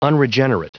Prononciation du mot unregenerate en anglais (fichier audio)
Prononciation du mot : unregenerate